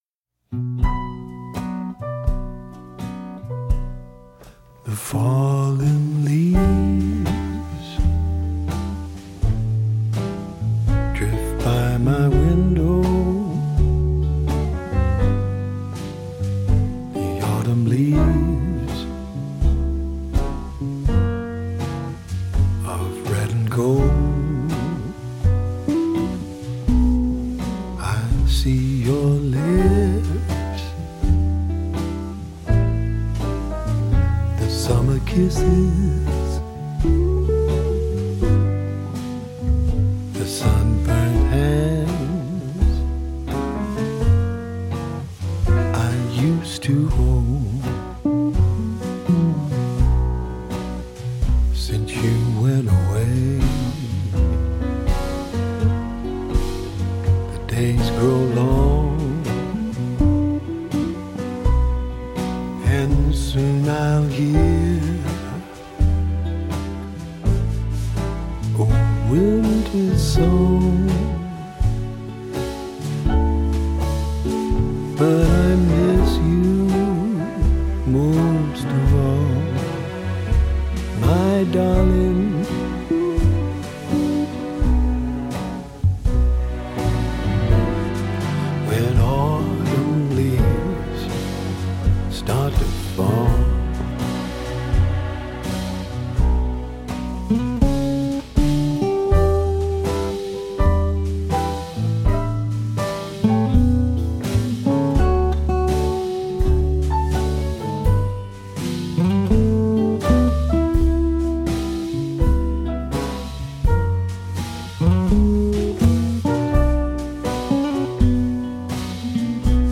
Random falling leaves with background music
jazz standard